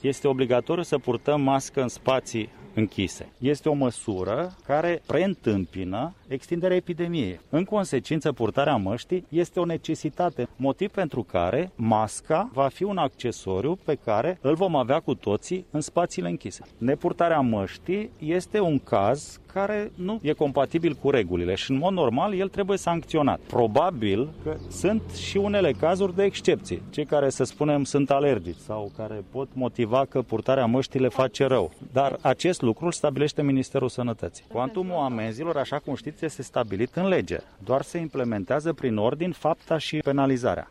A nu purta mascã de protecție în spații închise este o situație care în mod normal trebuie sancționatã, a mai declarat ministrul de Interne, la Nãdlac.